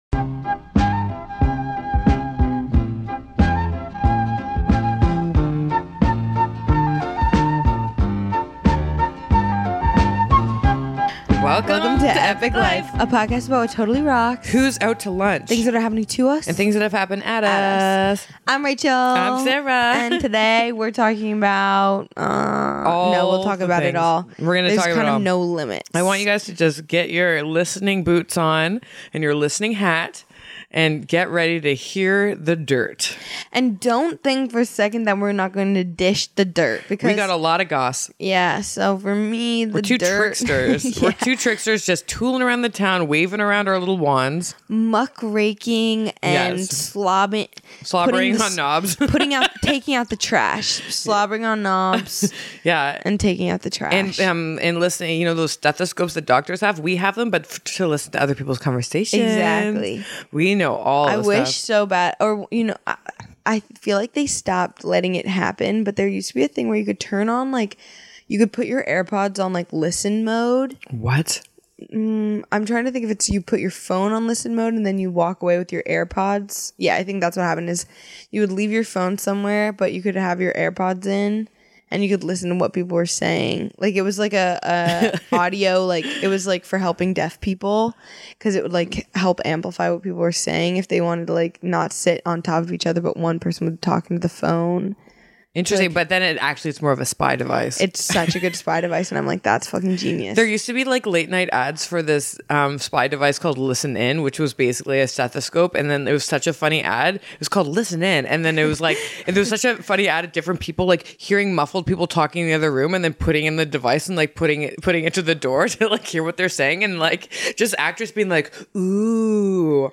The gals gab about what hurts, their big struggles, feeling bad, and what on earth is self worth!